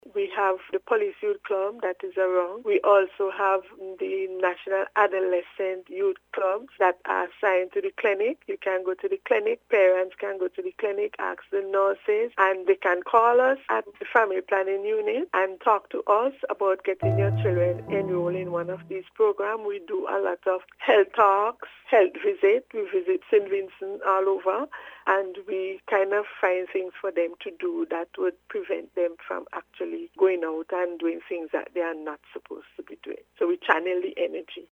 YOUTH-PROGRAMS.mp3